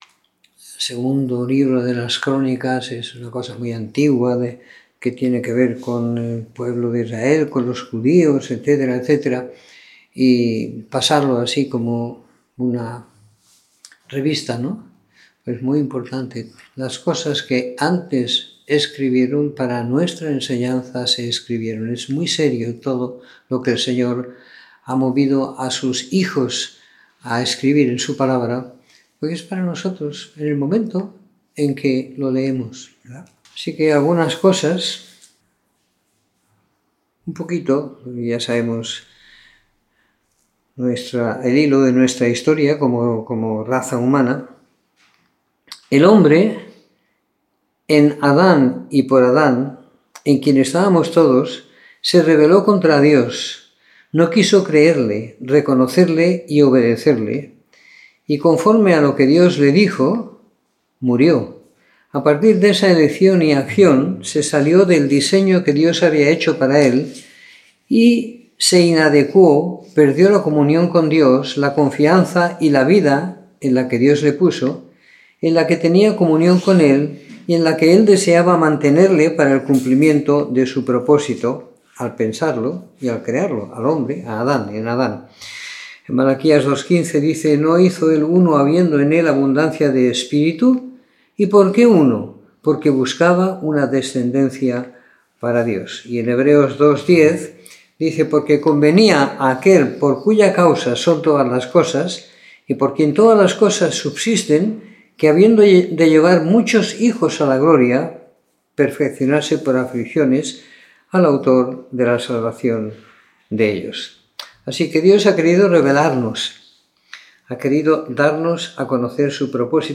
Comentario en 2º libro de Crónicas del capítulo 1 al 20 siguiendo la lectura programada para cada semana del año que tenemos en la congregación en Sant Pere de Ribes.